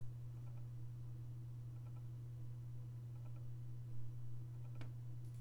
Geräusche Netzteil?
Hallo, aus meinem PC kommen seit ein paar Tagen komische "klacker" Geräusche. Hört sich so an, als würden die vom Netzteil kommen.